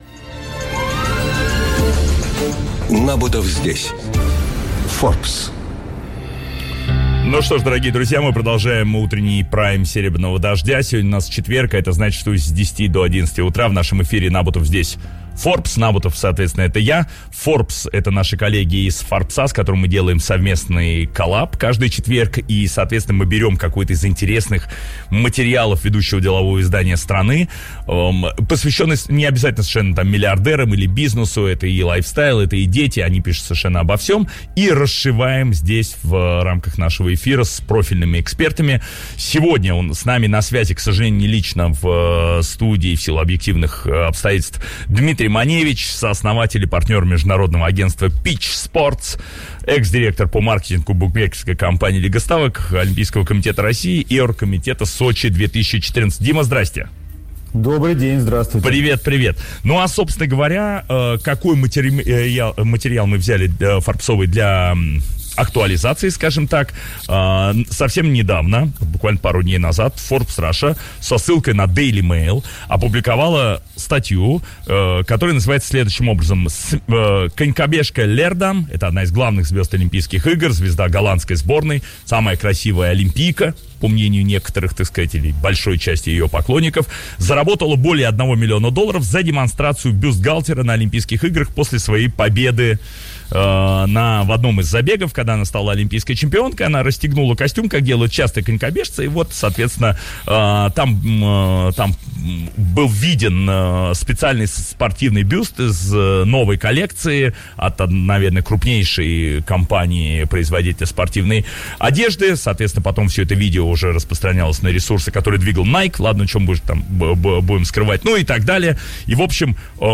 Каждый четверг в утреннем эфире радиостанции «Серебряный дождь» — программа «Набутов здесь. Forbes».